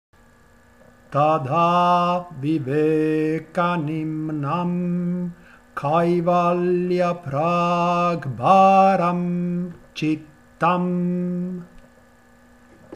Kaivalya padah canto vedico – Yoga Saram Studio